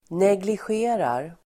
Ladda ner uttalet
Uttal: [neglisj'e:rar]